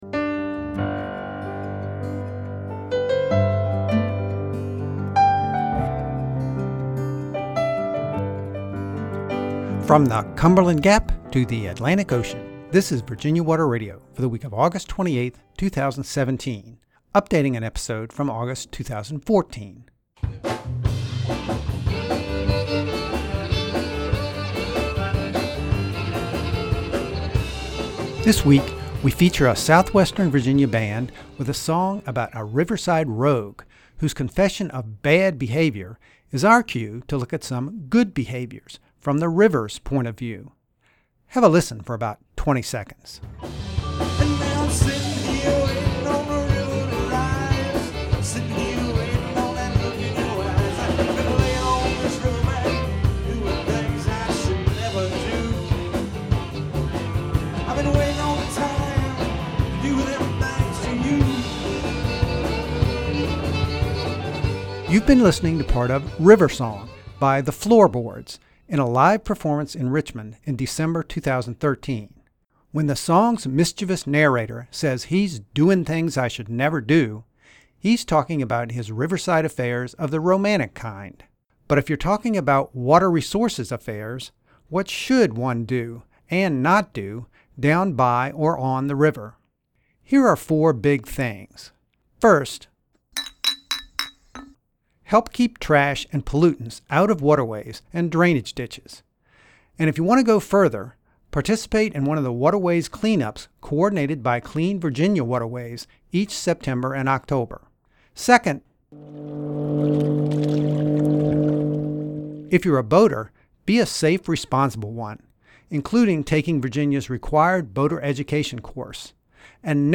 The excerpts of “River Songs” were taken from a live performance by The Floorboards at the Cary Street Cafe in Richmond, Va., on December 13, 2013; used with permission.